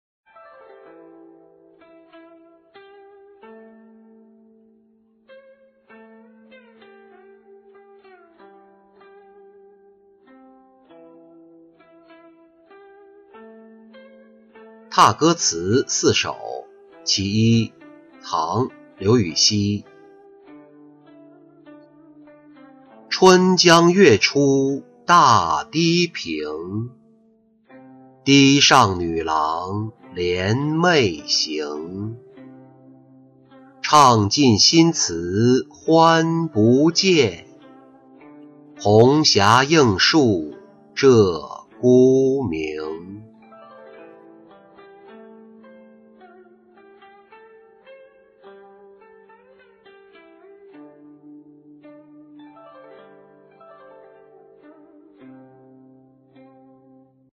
踏歌词四首·其一-音频朗读